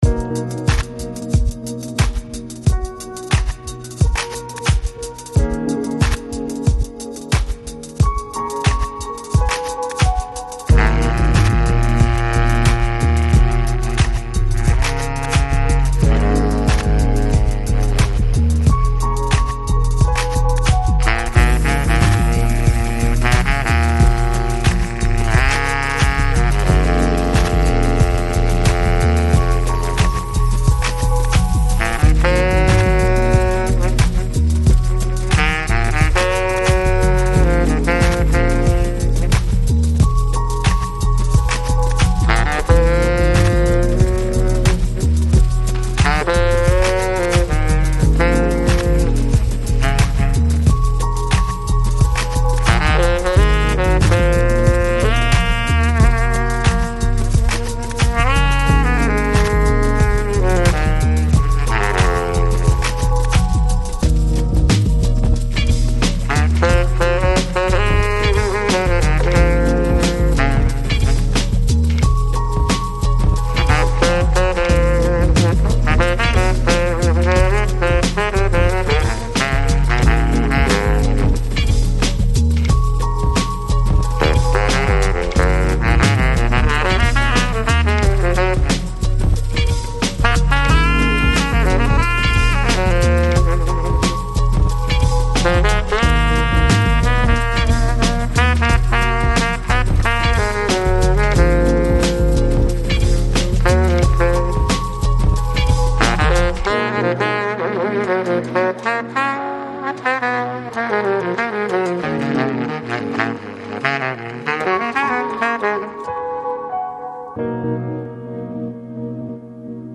Жанр: Chill Out, Downtempo, Lounge, Ambient, Jazz